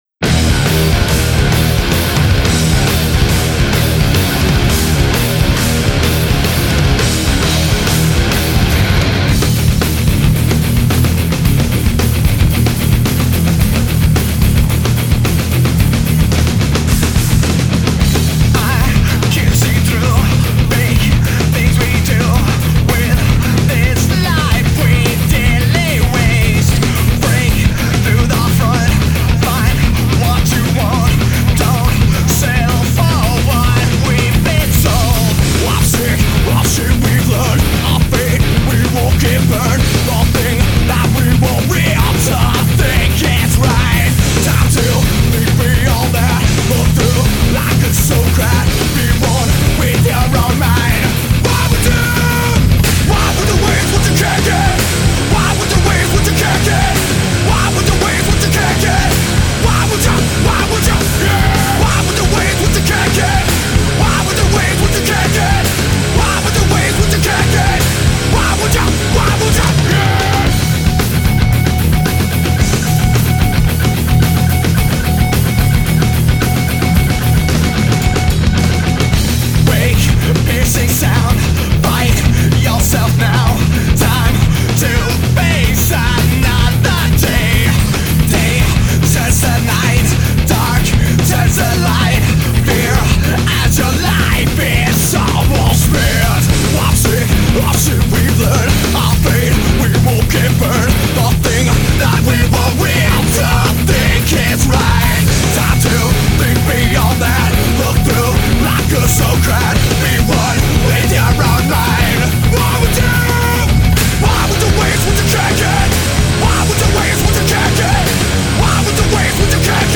Bass and Vocals
Drums